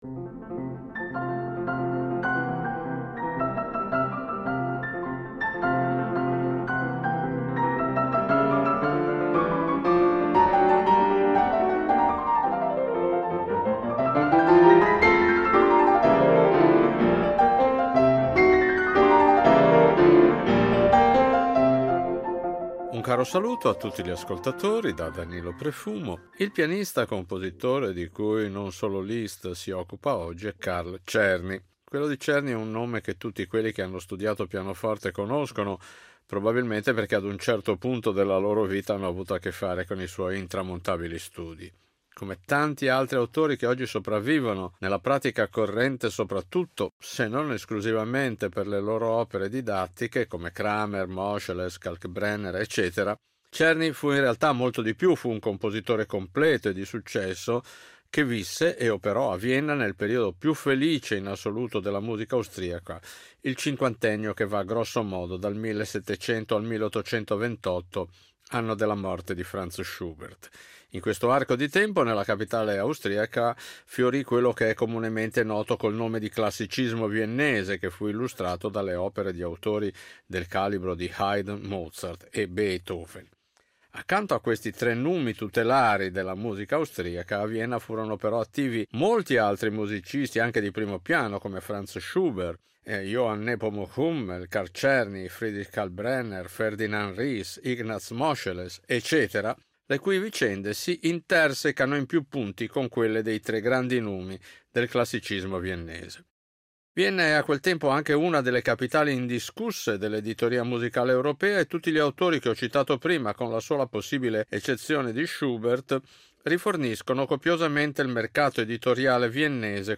La sua musica per pianoforte, pur risentendo dell’influenza beethoveniana, si distingue per uno stile brillante e virtuosistico, tipico del classicismo viennese.